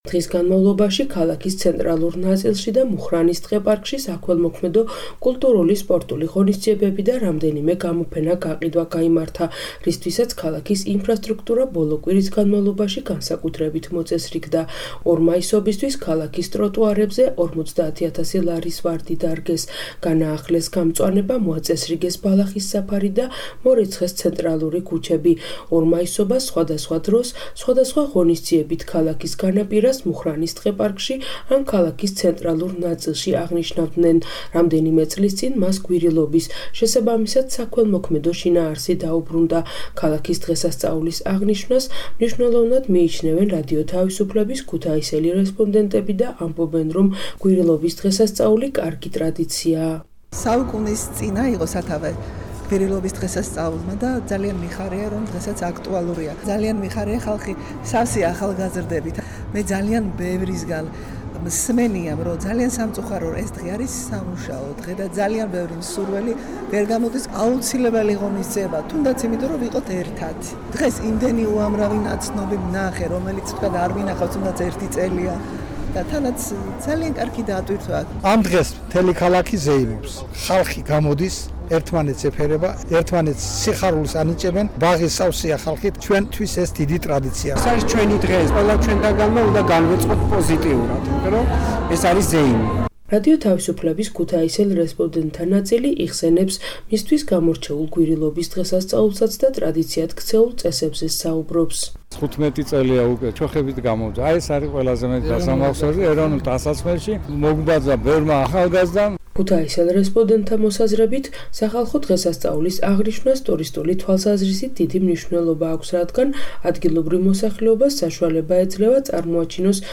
რადიო თავისუფლების ქუთაისელ რესპონდენტთა ნაწილი იხსენებს მისთვის გამორჩეულ გვირილობის დღესასწაულსაც და დამკვიდრებულ წესებზე საუბრობს.